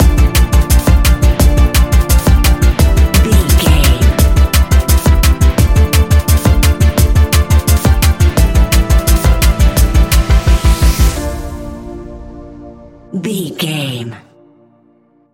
Ionian/Major
A♯
electronic
techno
trance
synths